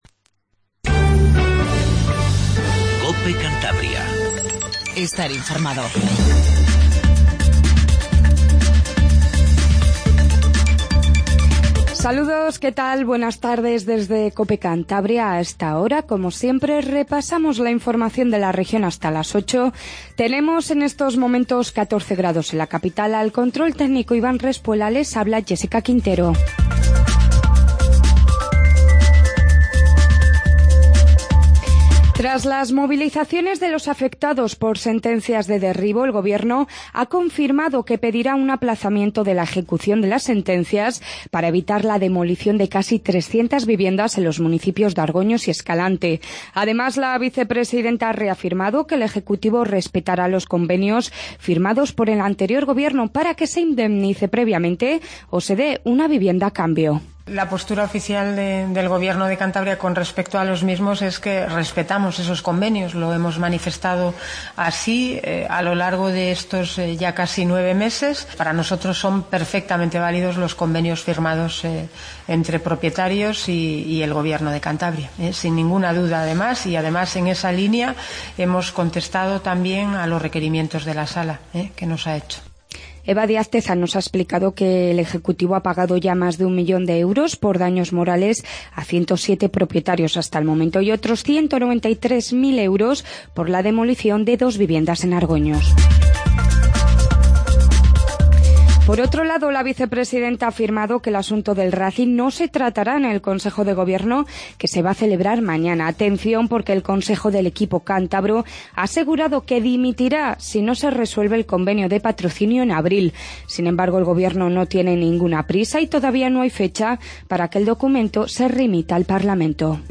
INFORMATIVO DE TARDE 19:50